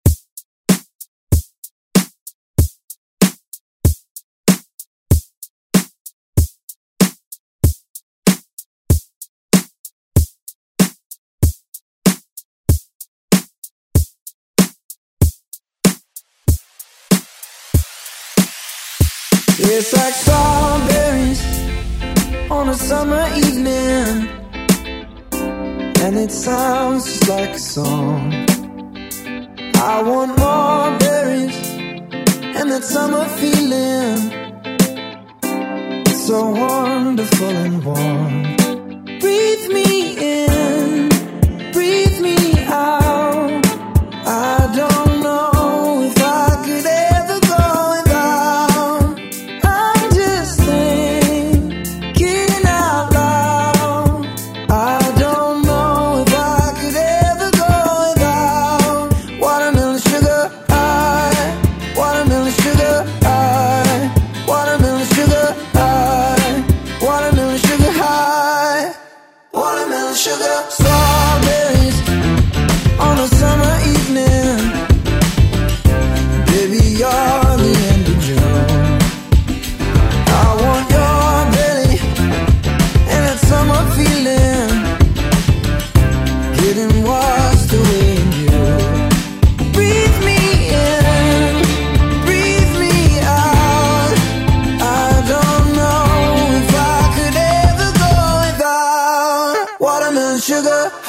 BPM: 95 Time